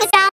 NRG Vocals.wav